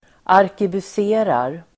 Ladda ner uttalet
Uttal: [arkebus'e:rar]